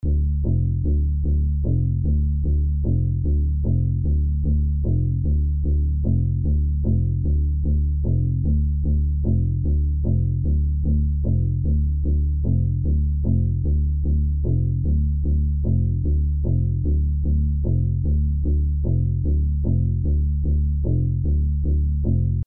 Bassline.mp3